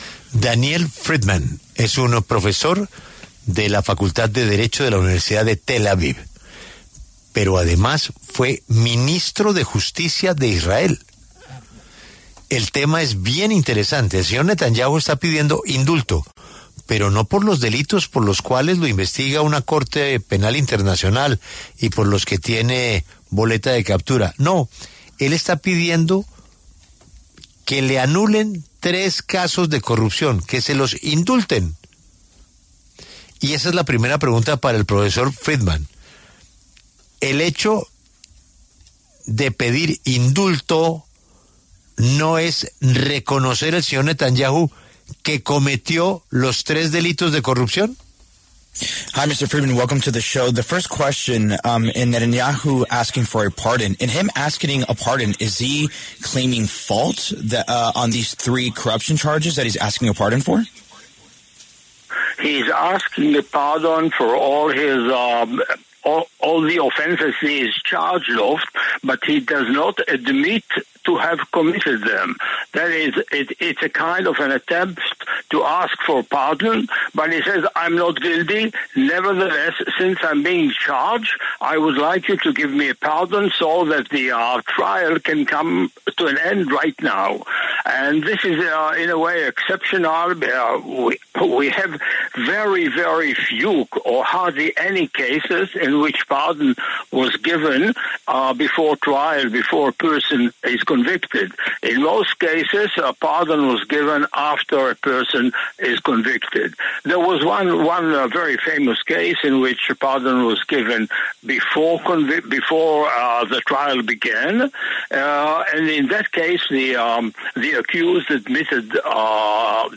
La W dialogó con Daniel Friedmann, exministro de Justicia de Israel, quien aseguró que se le puede otorgar el indulto a Benjamín Netanyahu, pero con condiciones.